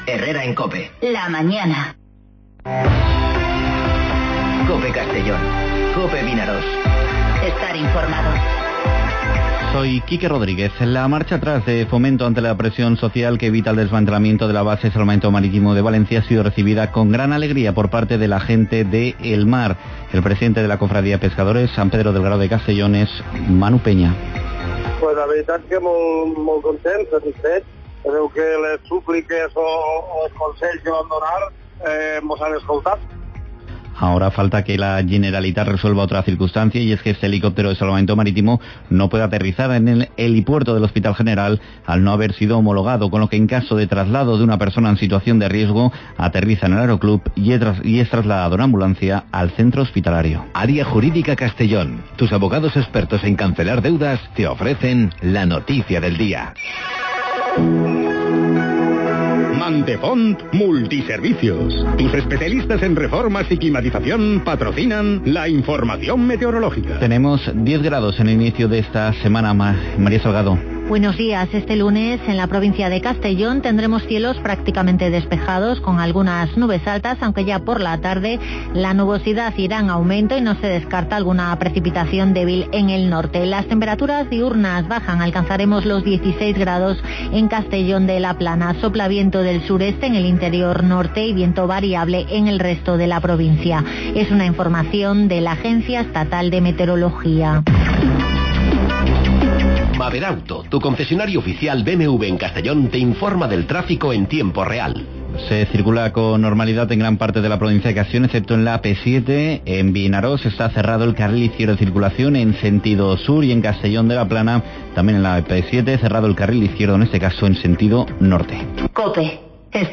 Informativo Herrera en COPE Castellón (16/12/2019)